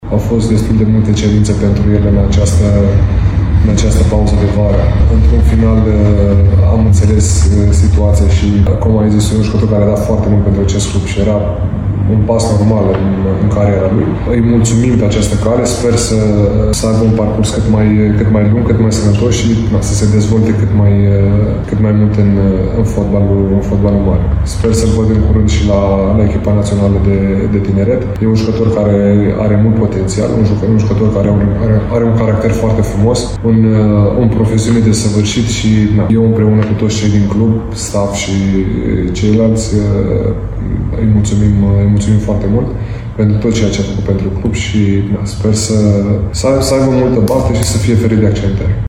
Într-un interviu acordat paginii de Facebook a clubului alb-violet